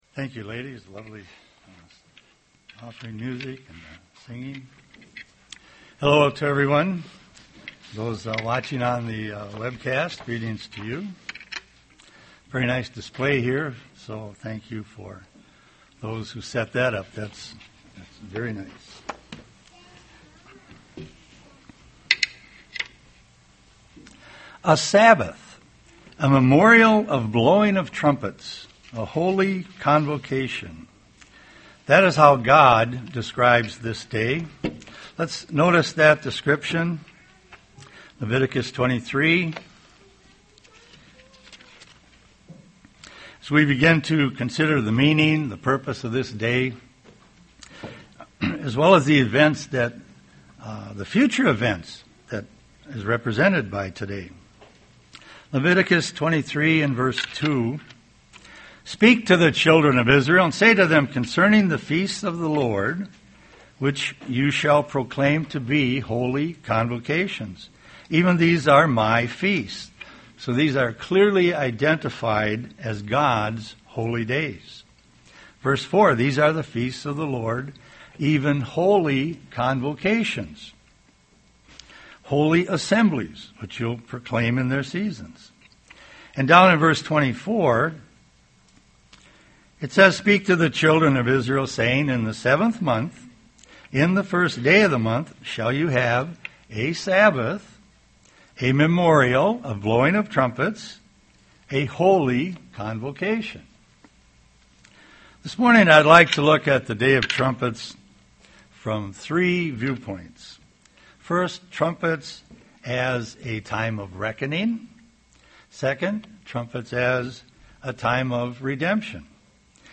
UCG Sermon Feast of Trumpets Restoration redemption destruction of the earth Studying the bible?